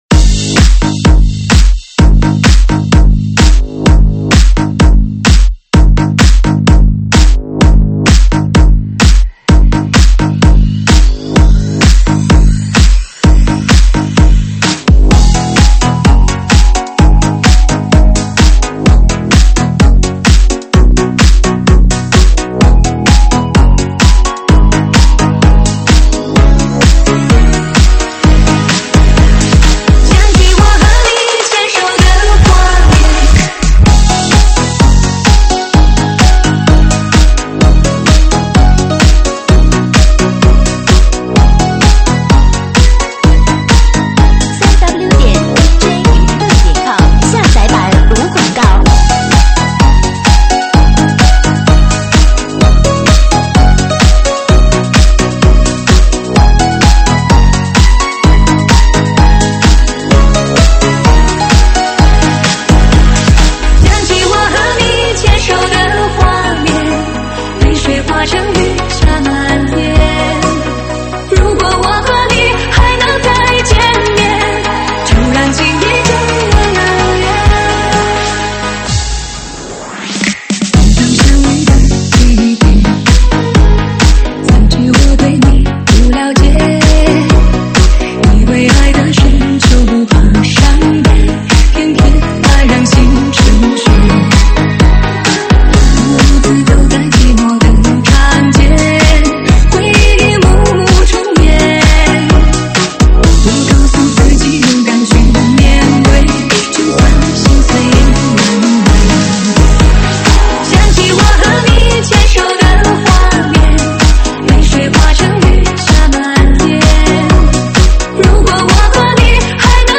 Remix版上传于：2025-04-11 05:03，收录于(中文舞曲)提供在线试听及mp3下载。